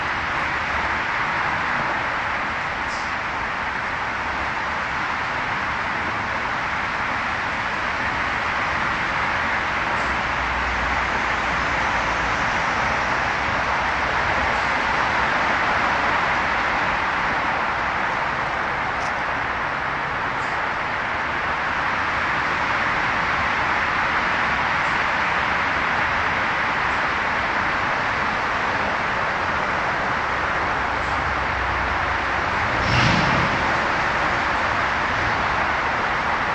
车水马龙
描述：从四车道高速公路下面的树木繁茂的区域和一个短的立交桥记录下来的交通流量的稳定轰鸣声。一只鸟偶尔唧唧喳喳，因为它是秋天。在录音结束时卡车的砰砰声。
标签： 道路 公路 交通 公园 汽车 现场录音 交通 噪音
声道立体声